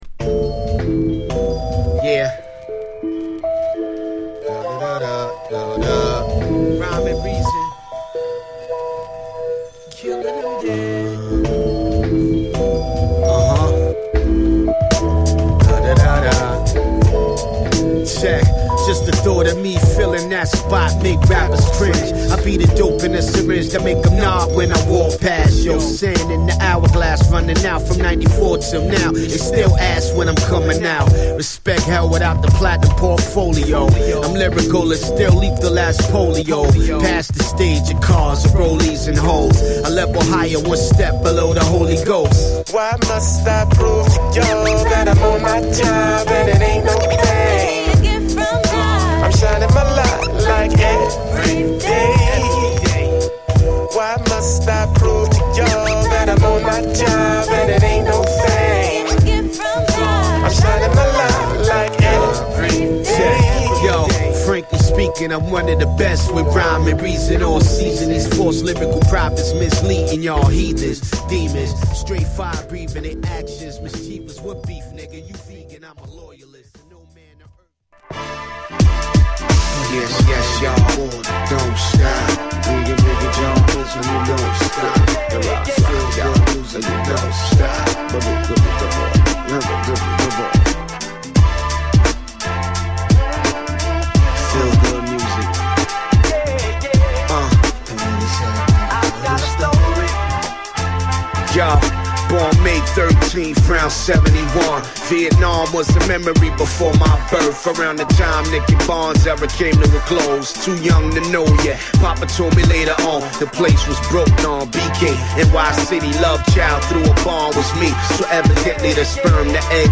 タイトル通りソウルフルなナイス・トラック満載！